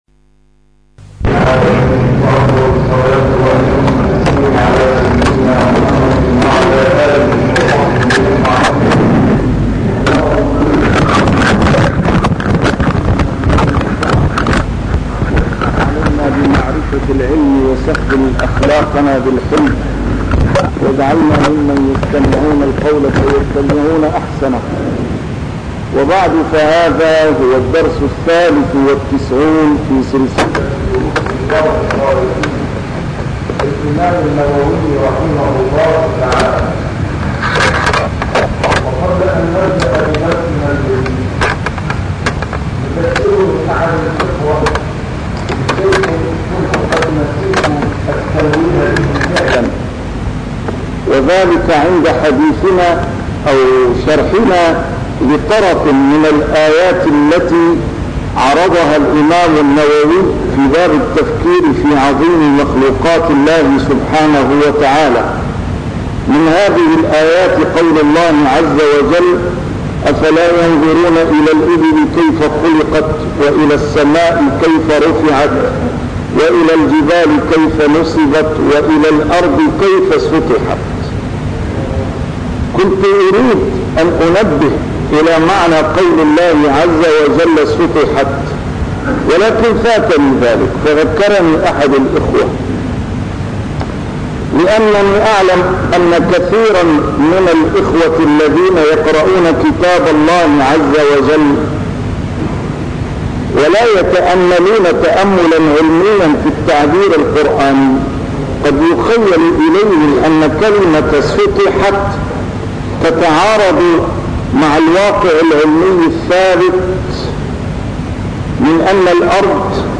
A MARTYR SCHOLAR: IMAM MUHAMMAD SAEED RAMADAN AL-BOUTI - الدروس العلمية - شرح كتاب رياض الصالحين - 93- شرح رياض الصالحين: المبادرة إلى الخيرات